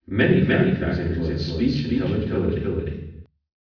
slap150ms.wav